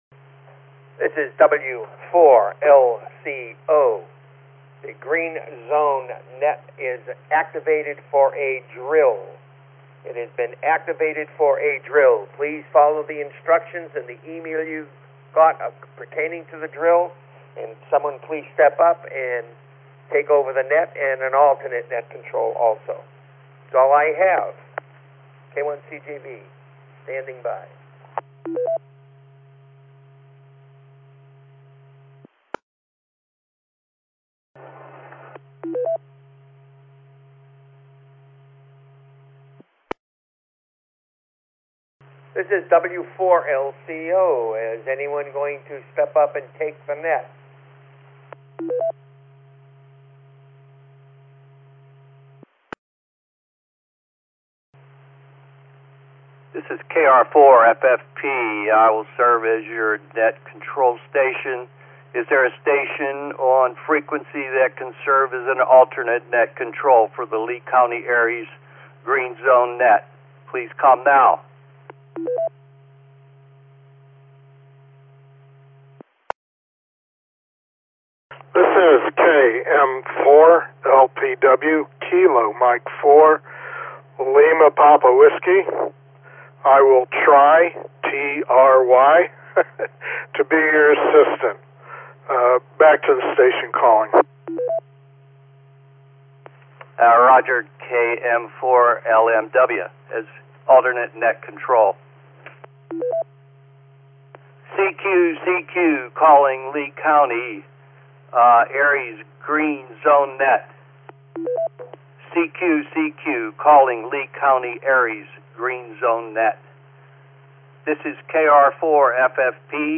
This is the recording of the LeeARES Green Zone Net Exercise on April 4, 2026